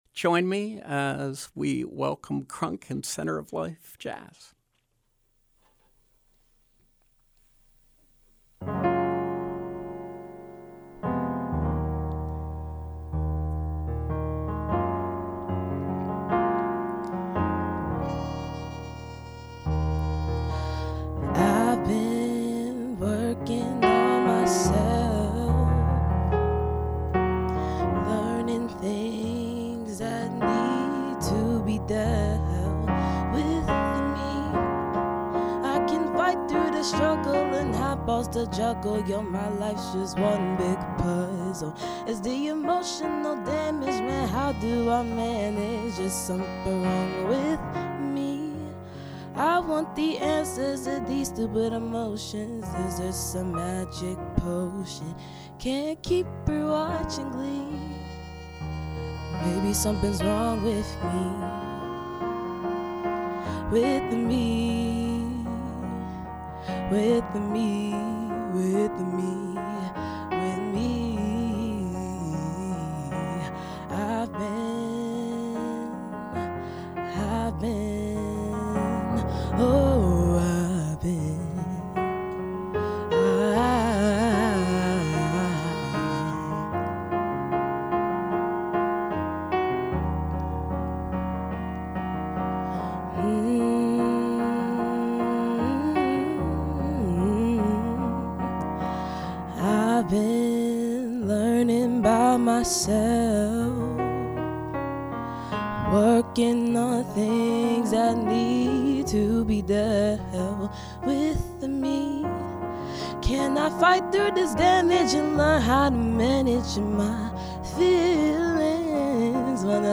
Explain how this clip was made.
live performances